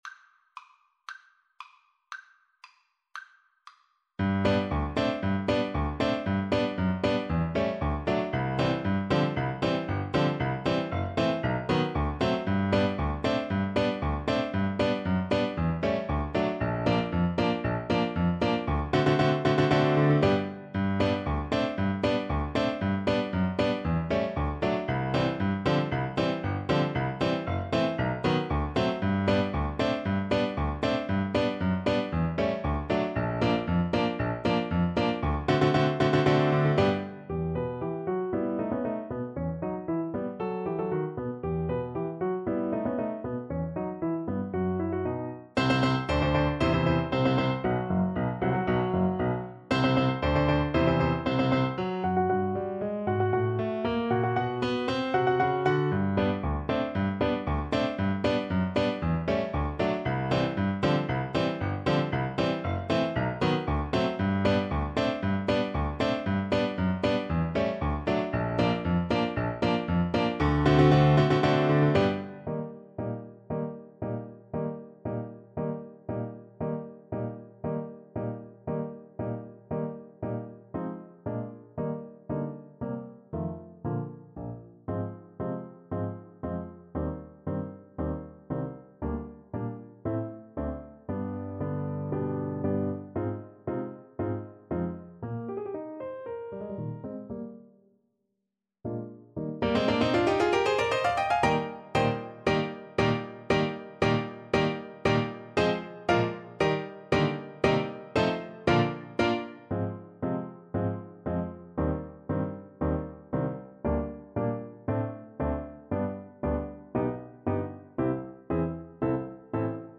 2/4 (View more 2/4 Music)
Classical (View more Classical Trombone Music)